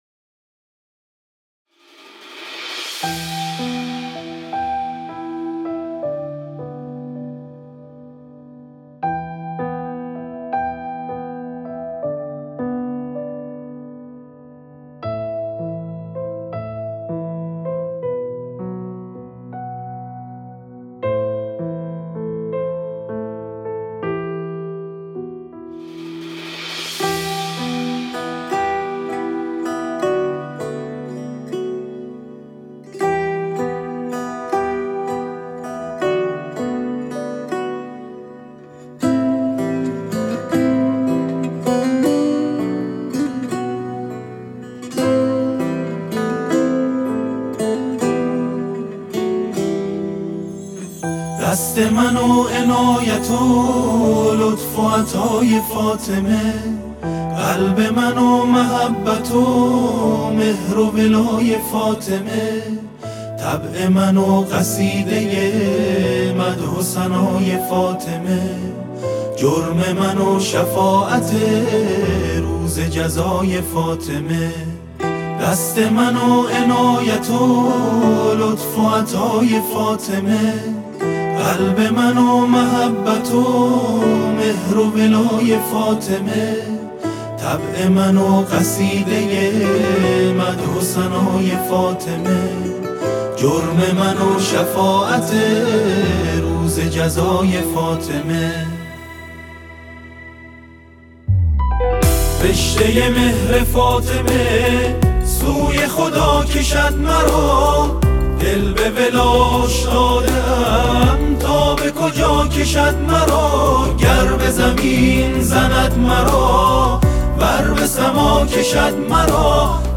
هم آوایی